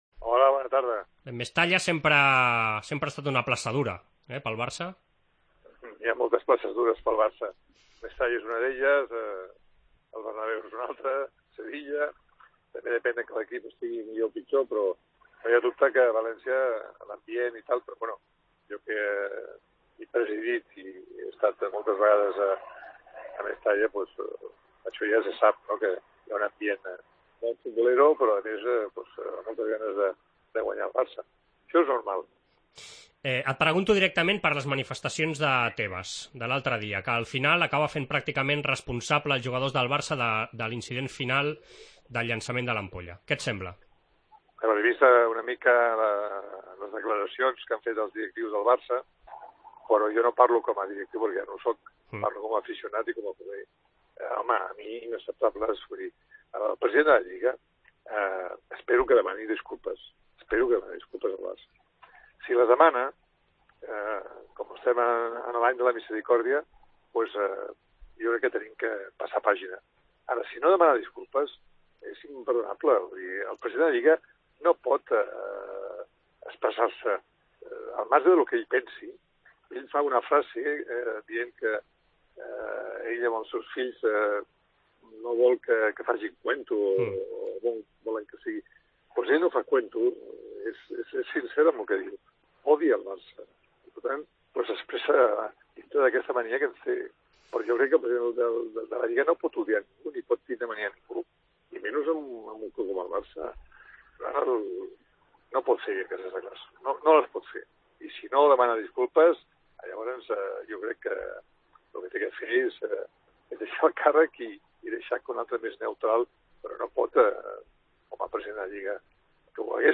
Entrevista Joan Gaspart